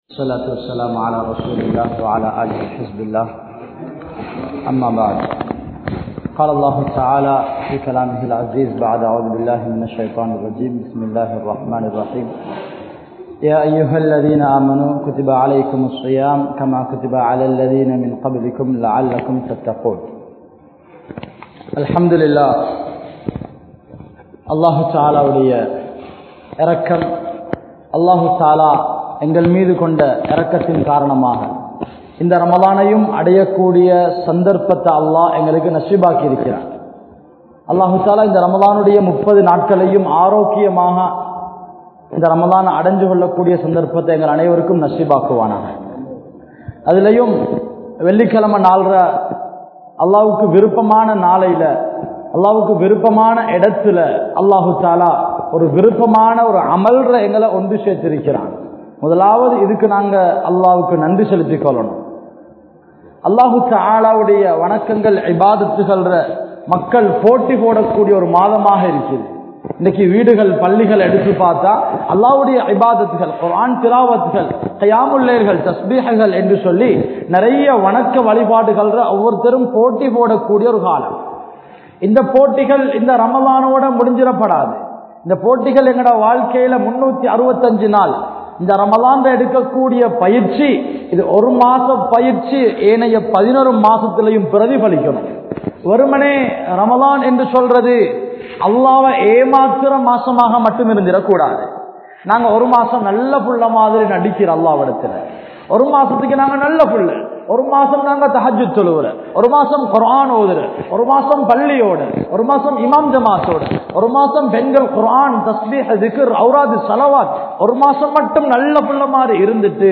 Vitta Paavangalai Thodaraatheerhal (விட்ட பாவங்களை தொடராதீர்கள்) | Audio Bayans | All Ceylon Muslim Youth Community | Addalaichenai
Matala, Warakantha Jumua Masjidh